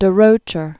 (də-rōchər, -shər), Leo Ernest 1905-1991.